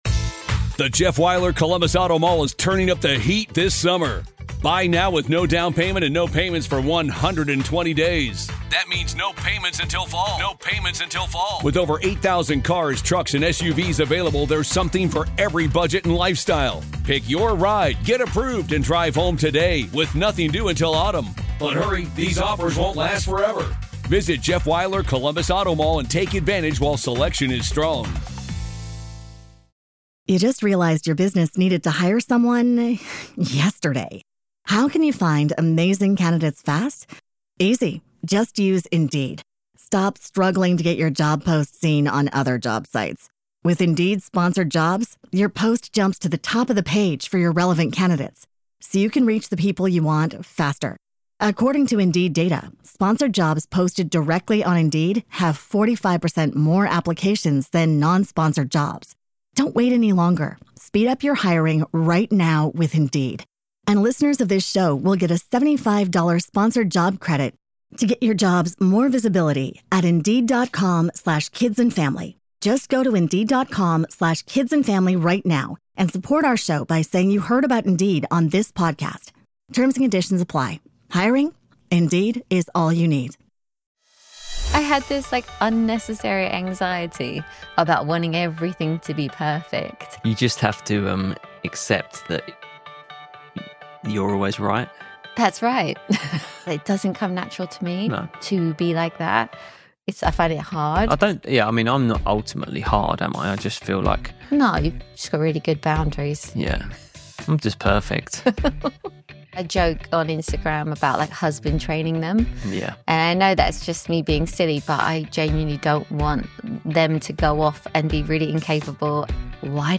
Expect some classic British sarcasm, plenty of real talk, and a behind-the-scenes look at the things no one tells you about raising kids.